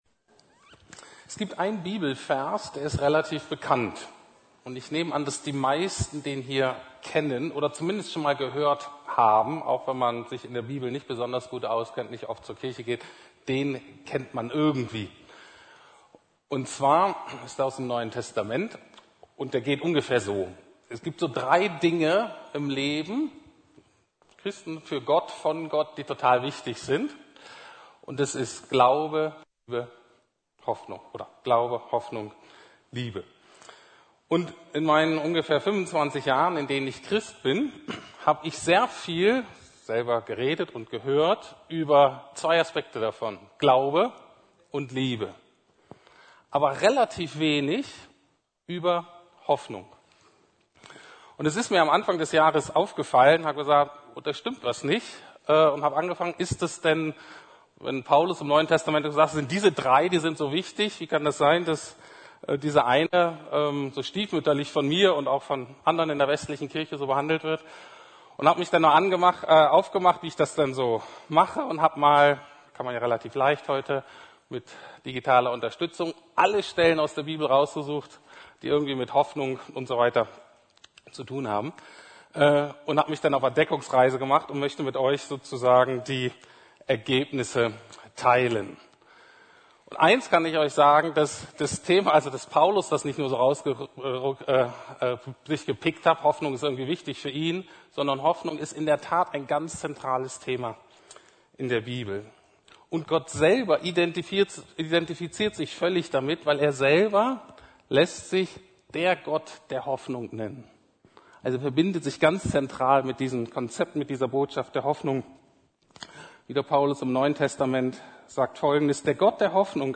Der Gott der Hoffnung - Teil 1 ~ Predigten der LUKAS GEMEINDE Podcast